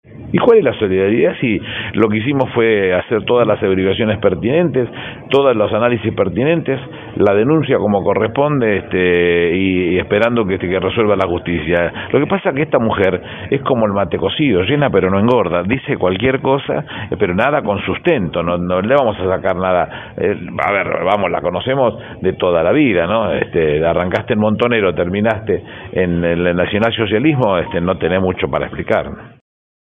"Lo que hicimos fue hacer todas las averiguaciones pertinentes, todos los análisis pertinentes y la denuncia como corresponde. Estamos esperando que resuelva la Justicia. Lo que pasa con esta mujer es que es como el mate cocido: llena pero no engorda. Dicen muchas cosas, pero nada con sustento, la conocemos de toda la vida: arrancó en Montoneros y terminó en el nacional socialismo, no tenés mucho para explicar", expresó Fernández en diálogo con periodistas acreditados en la Casa Rosada.